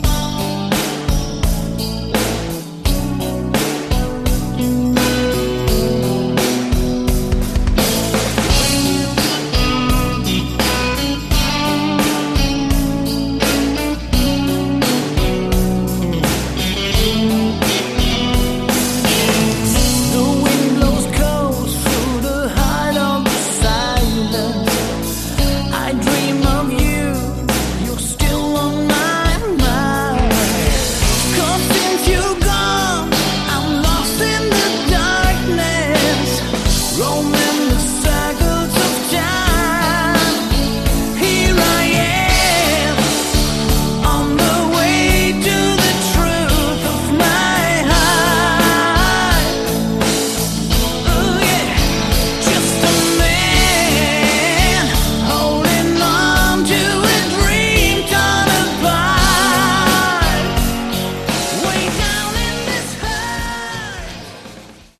Category: Hard Rock
lead and backing vocals
guitars, keyboards, backing vocals
bass, backing vocals